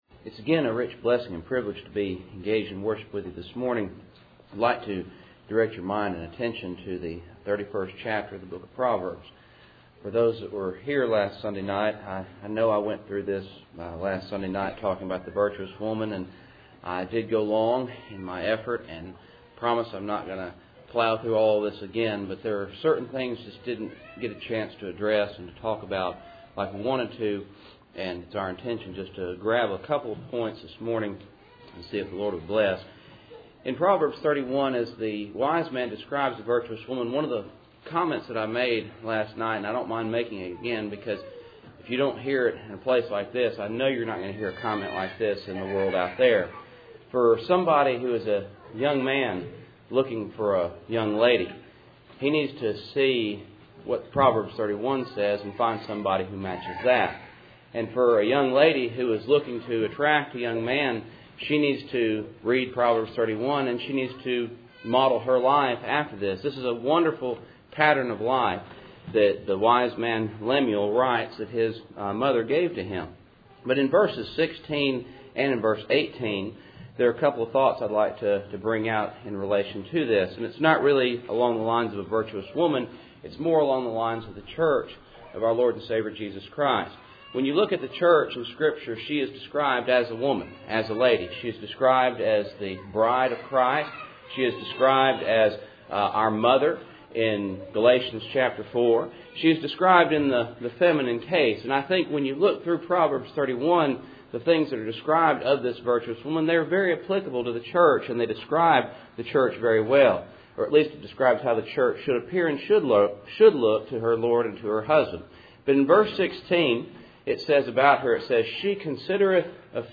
Passage: Proverbs 31:16-18 Service Type: Cool Springs PBC Sunday Morning %todo_render% « The Virtuous Woman I Peter 1:22-25